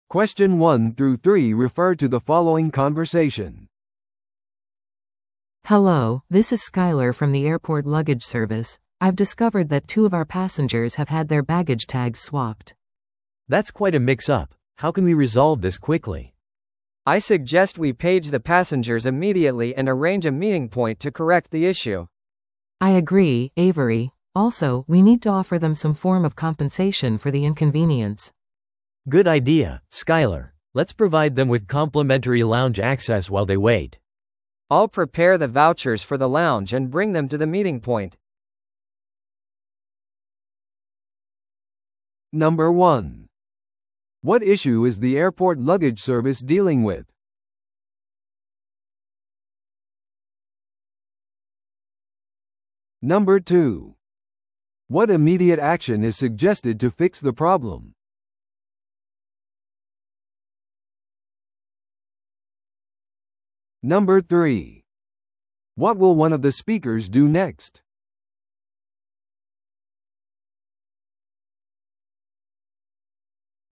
TOEICⓇ対策 Part 3｜空港での荷物タグの取り違えについて – 音声付き No.104